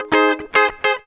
GTR 89 G#MAJ.wav